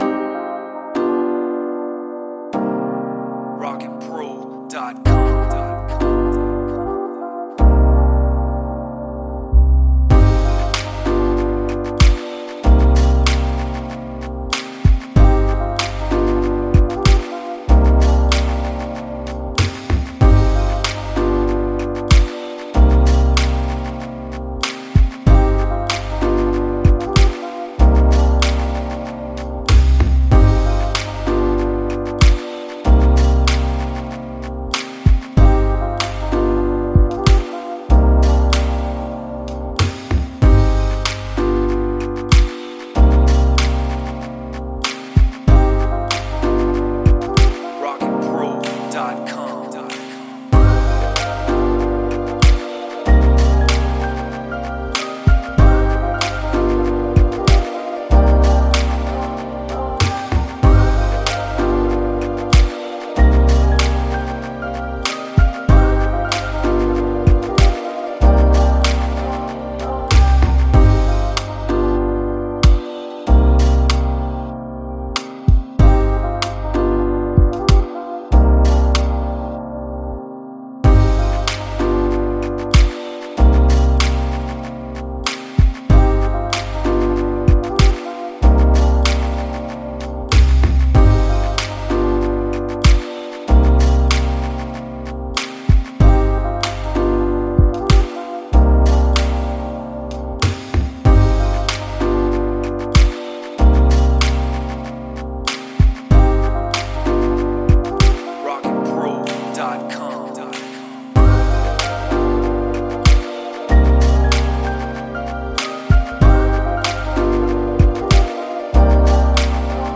Soulful
91 BPM.